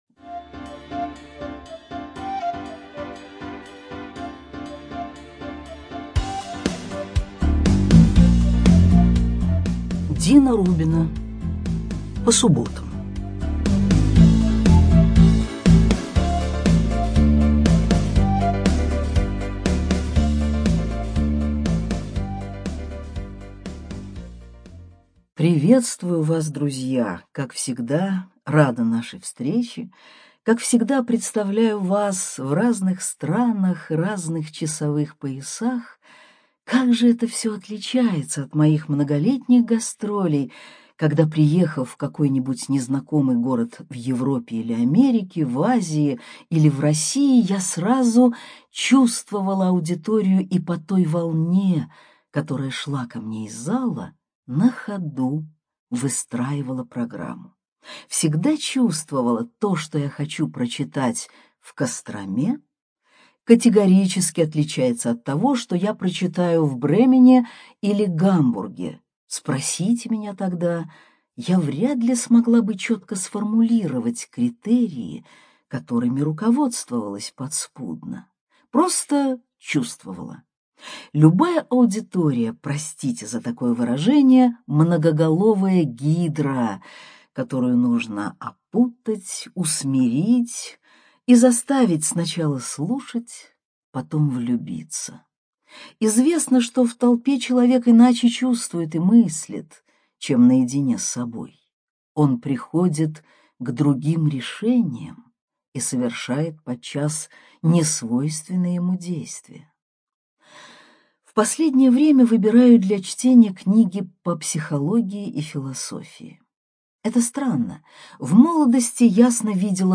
ЧитаетАвтор
Студия звукозаписивимбо